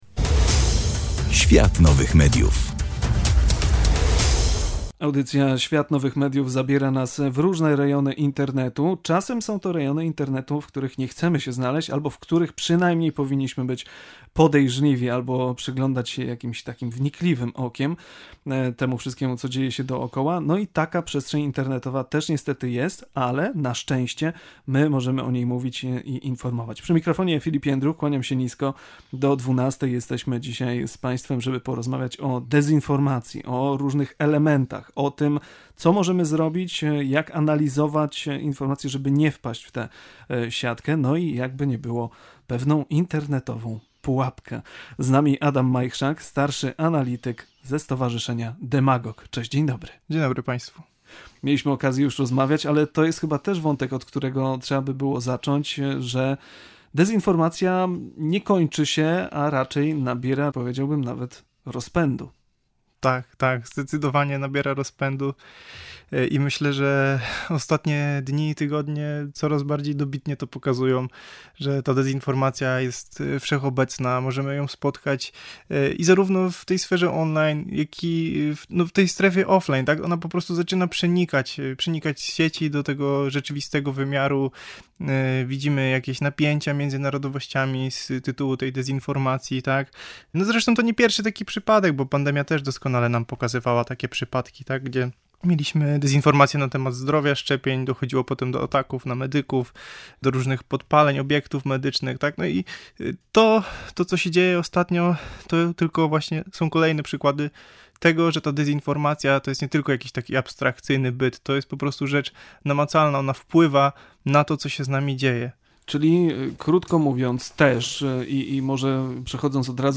W audycji „Świat Nowych Mediów” rozmawialiśmy o dezinformacji – czym jest, jakie są jej skutki i jak możemy się przed nią chronić.